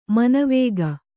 ♪ manavēga